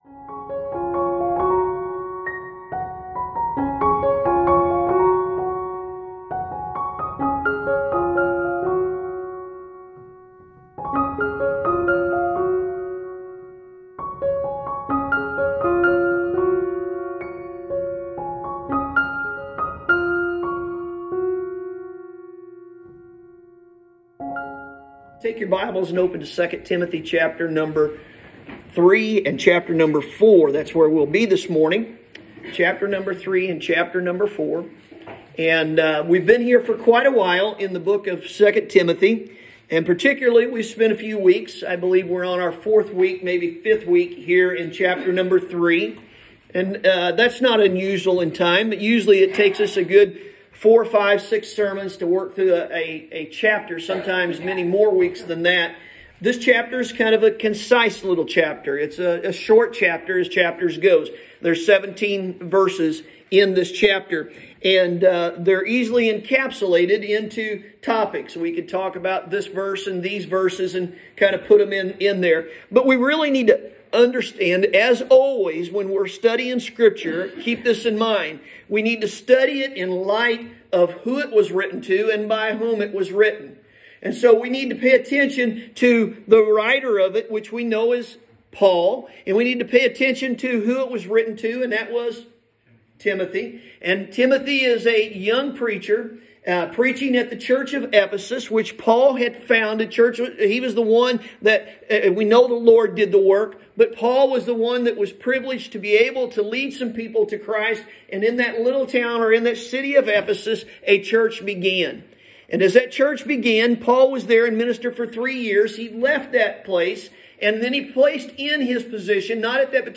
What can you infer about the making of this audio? Sunday Morning – February 14th, 2021